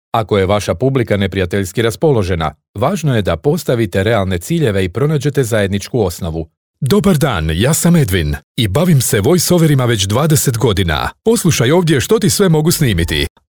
Croatian male voice talent